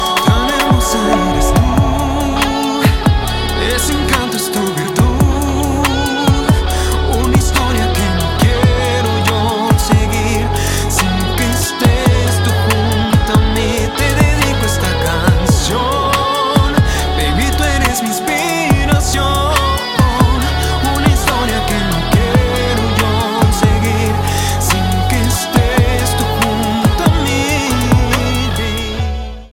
es pop romántico de corazón grande